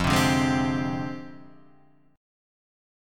F Minor 6th Add 9th